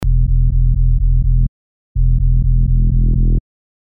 Специально для тебя убрал песнопения и оставил только барабаны с басом )
Немного фильтр заодно прикрыл
как будто идешь по улице и мимо проезжает вот "это" (с сабом и дребезжанием всего и вся в багажнике).